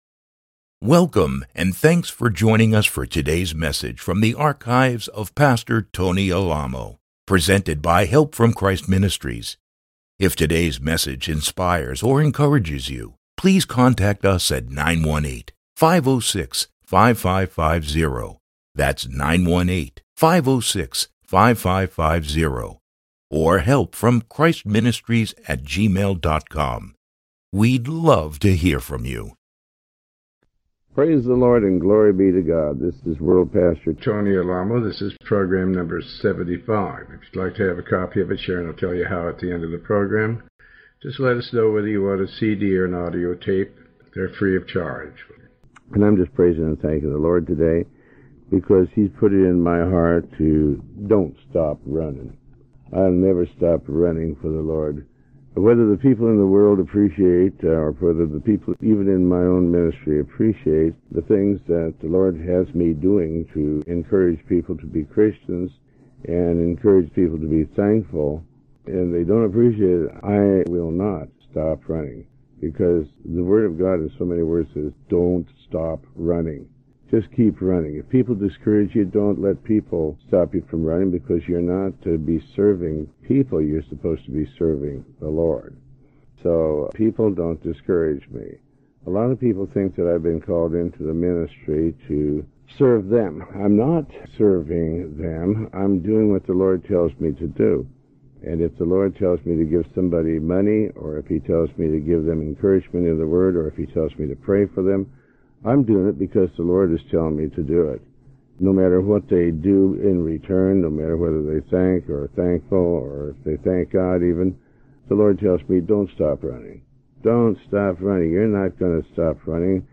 Sermon 75